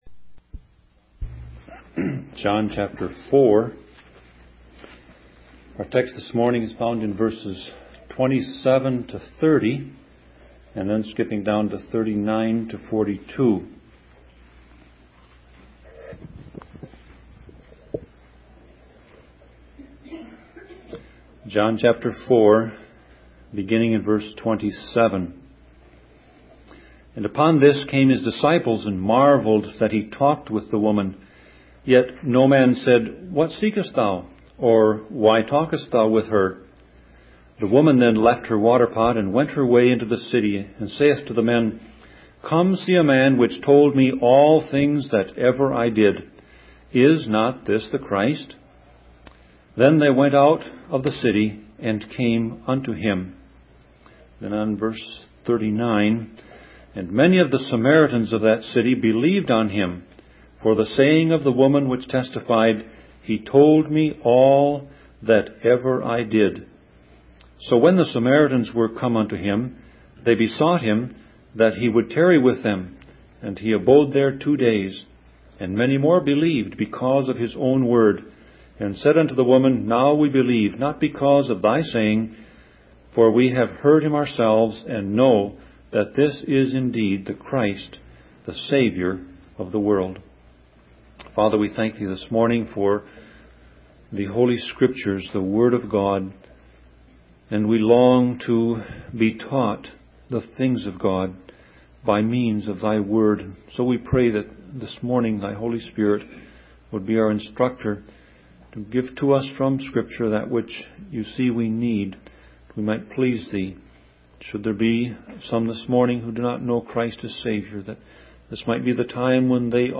Sermon Audio Passage: John 4:27-30 Service Type